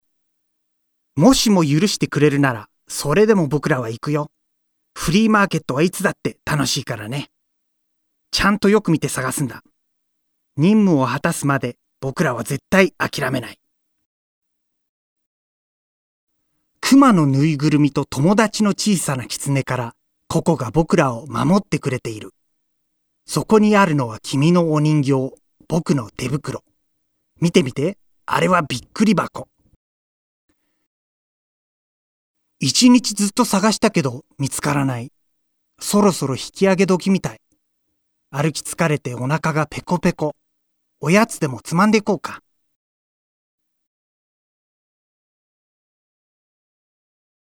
Sprecher japanisch. Friendly, Genuine, Happy, Humorous, Natural, Youthful.
Sprechprobe: Werbung (Muttersprache):
Male Japanese Voice Over. Friendly, Genuine, Happy, Humorous, Natural, Youthful.